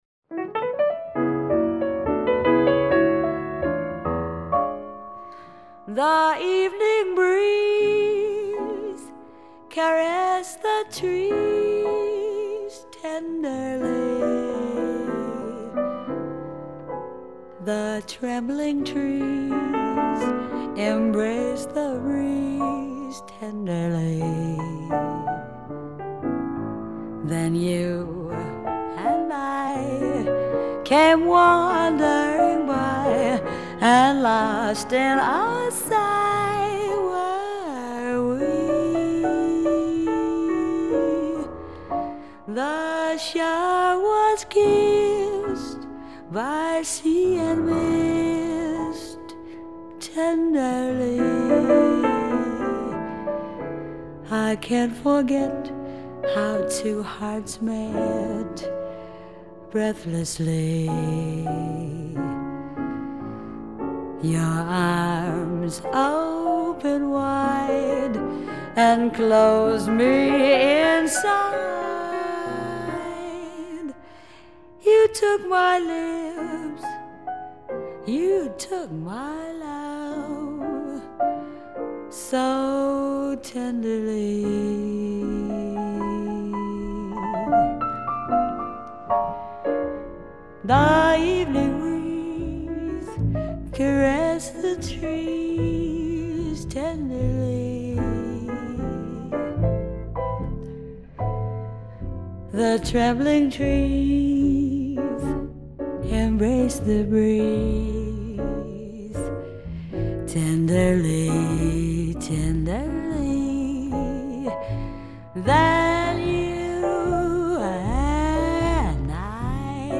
这张专辑既有炫技快歌，也有抒情慢歌，味道十足，非常耐听。
专辑类型：JAZZ
略带点沙哑的歌声，但多是轻快的演绎